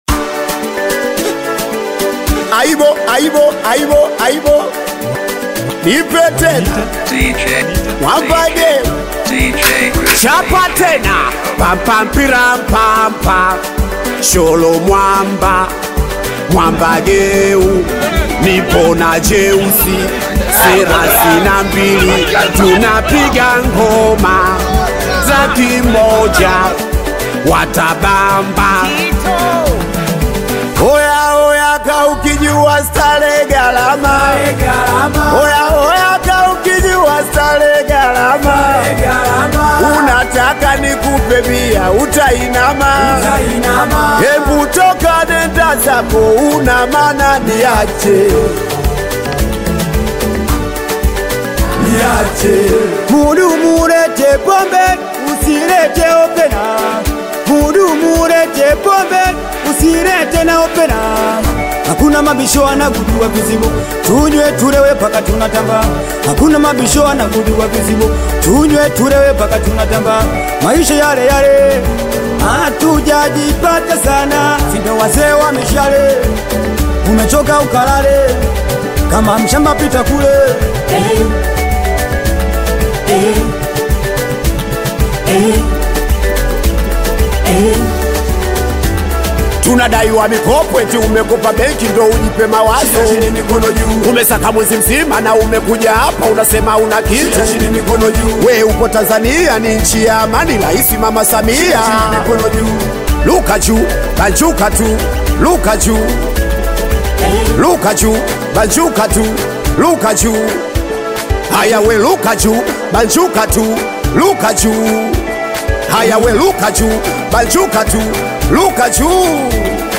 SINGELI